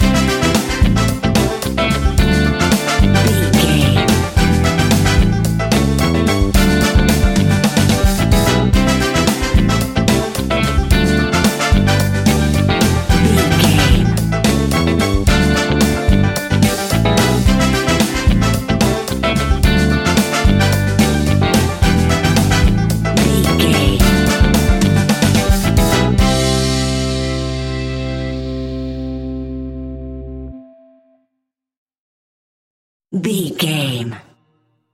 Ionian/Major
latin
cuban music
uptempo
saxophone
trumpet